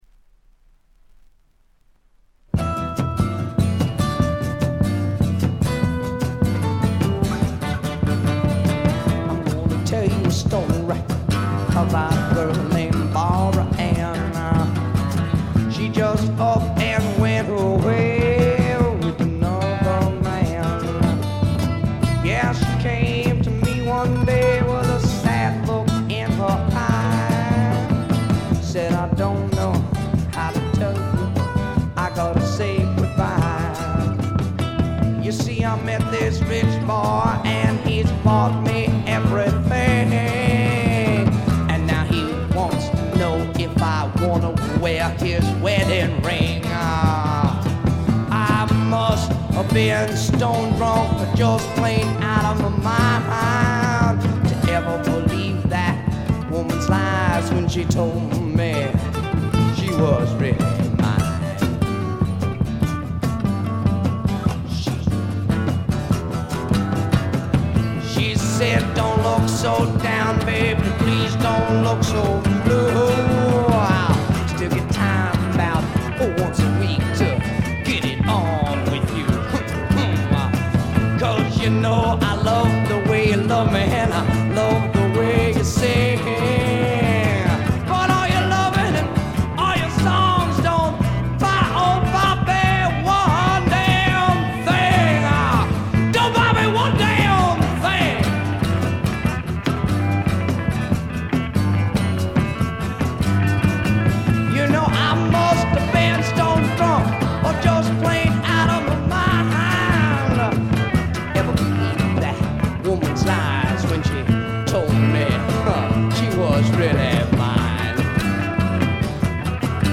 ほとんどノイズ感無し。
試聴曲は現品からの取り込み音源です。
Vocals, Guitar